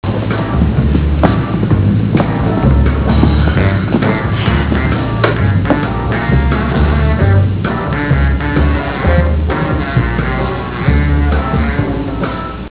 Classical/Instrumental
Comment: saxophone...campy